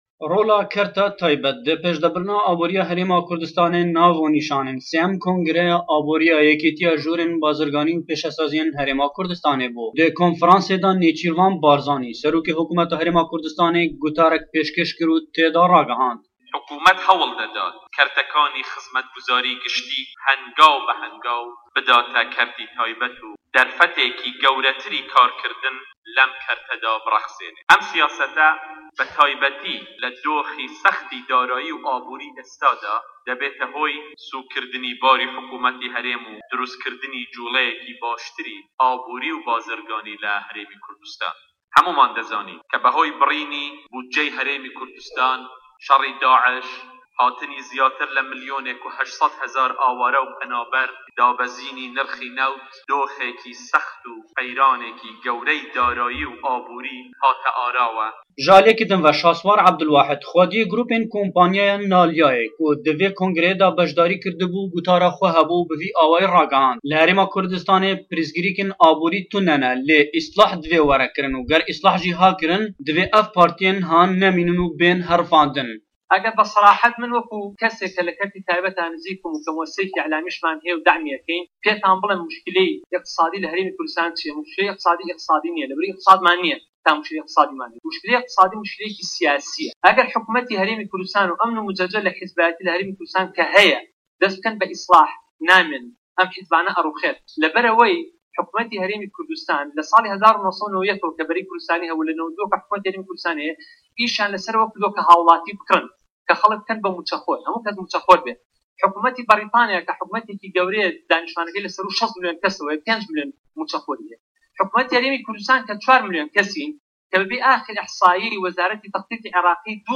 نێچيرڤان بارزانى سه‌رۆكى حكومه‌تى هه‌رێمى كوردستان گوتارێكى پێشکەش کرد و لە به‌شێكى گوتاره‌كه‌يدا ڕایگەیاند ئه‌گه‌ر حكومه‌تى عێراق بودجه‌ى هه‌رێمى كوردستانى نەبڕیبایە و شه‌ڕى داعش و ئه‌و ژماره‌ زوره‌ى ئاواره‌ و په‌نابه‌ران نه‌بوونايه‌ كه‌ ڕۆژانه‌ مليۆنه‌ها دۆلار له‌ سه‌ر حكومه‌تى هه‌رێمى كوردستان ده‌كه‌وێت و هه‌روه‌ها له‌ هه‌مووى خرابتر دابه‌زينى نرخ نه‌وت نه‌بووايه‌ حكومه‌تى هه‌رێمباشتر و به‌ ده‌ستێكى كراوه‌تر هاوكار و هه‌ماهه‌نگى كه‌رتى تايبه‌تى ده‌كرد.